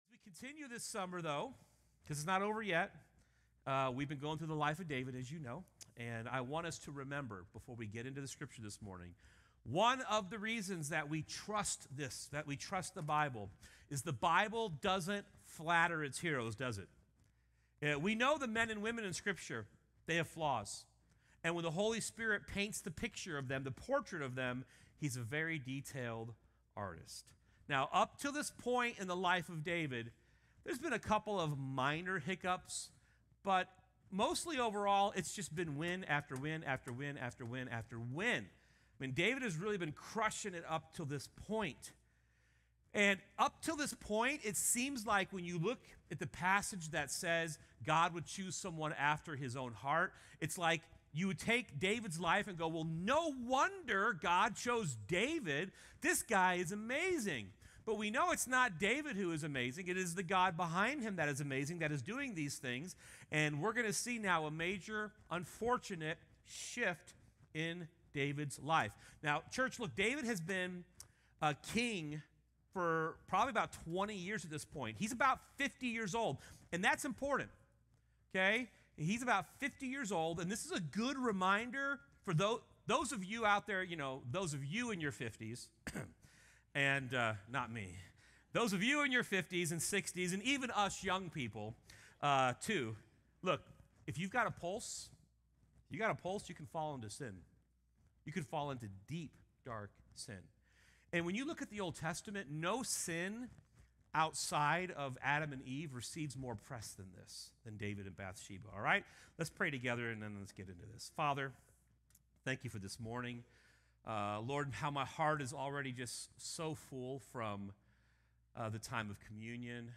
A message from the series "The Life of David."
sermon